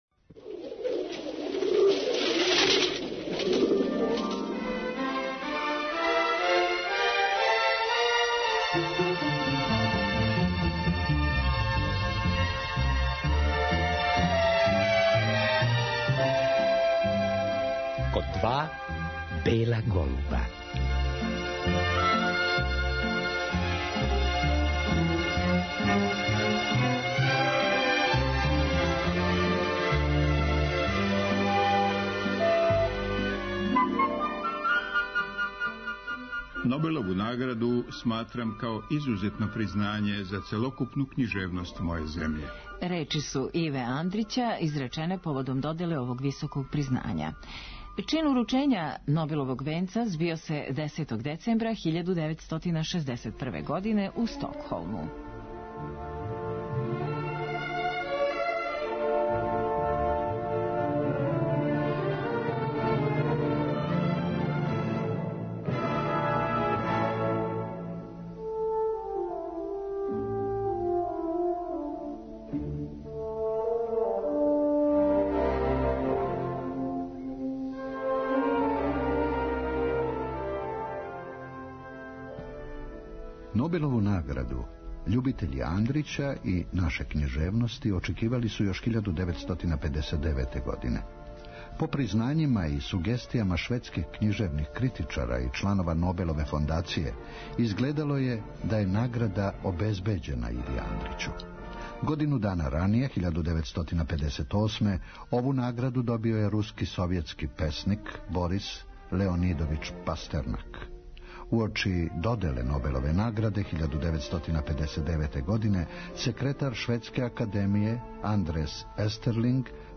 Слушаћемо и делове беседе коју је Андрић изговорио, на француском језику, приликом уручења Нобелове награде а сазнаћемо и како је изгледала сама церемонија доделе овог признања у Стокхолму.